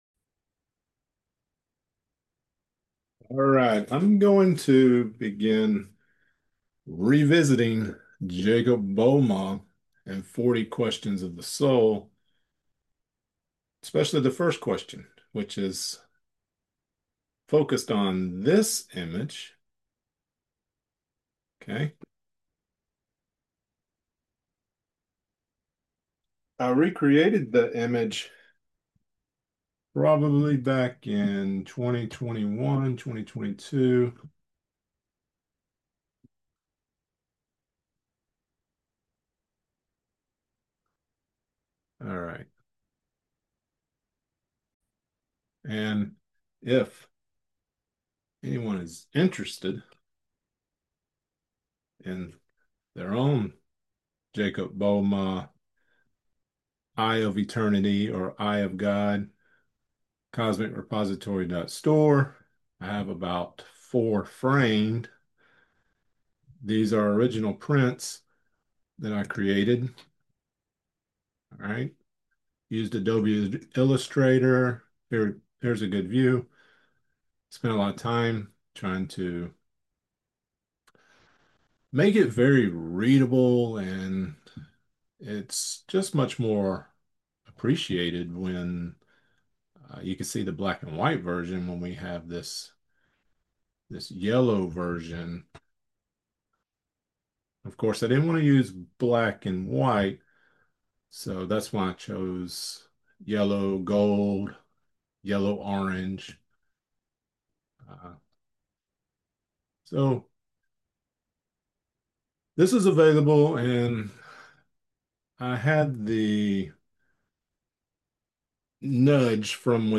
Within this lecture, we discuss: Ternary: threefold self-manifestation of God: The Fire Principle — severity, desire, wrath, hidden power.